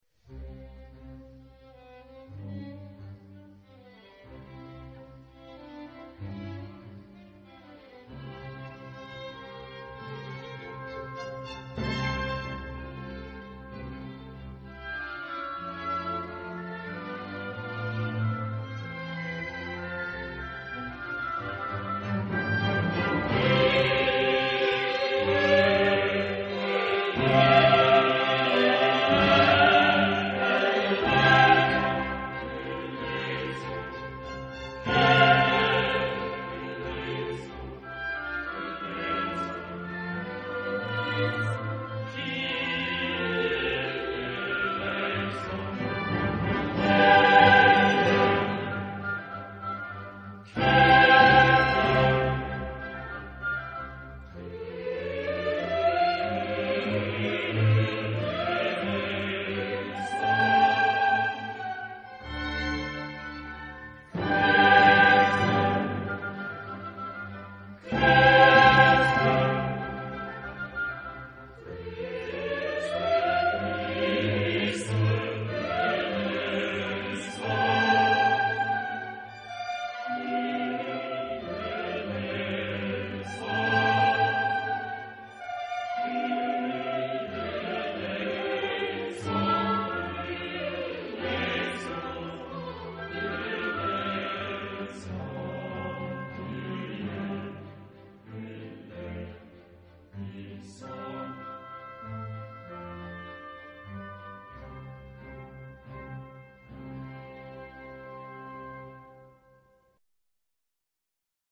Genre-Style-Form: Sacred ; Mass ; Classic
Type of Choir: SATB  (4 mixed voices )
Soloist(s): SATB  (4 soloist(s))
Instrumentation: Chamber orchestra  (16 instrumental part(s))
Instruments: Violin (2) ; Viola (1) ; Cello (1) ; Oboe (2) ; Bassoon (2) ; Trumpet (2) ; Trombone (3) ; Timpani (2) ; Double bass (1) ; Organ (1)
Tonality: C major